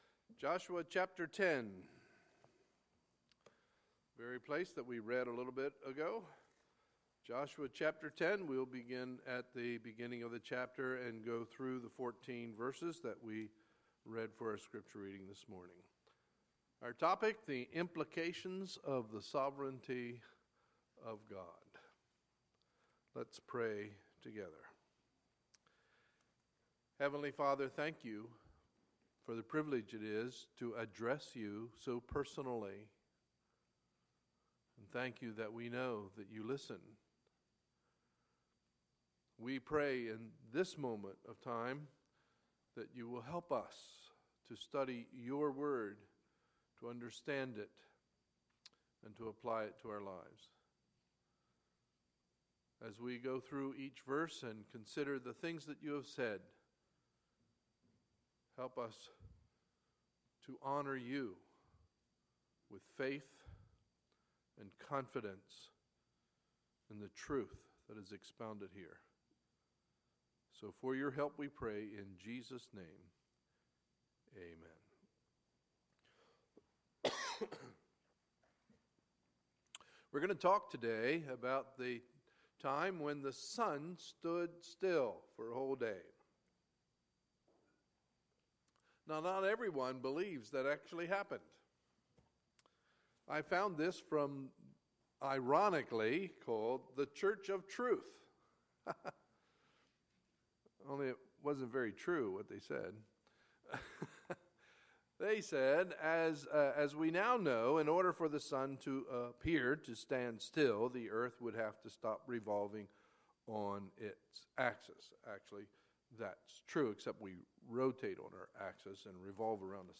Sunday, August 28, 2016 – Sunday Morning Service